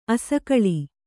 ♪ asakaḷi